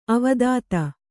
♪ avadāta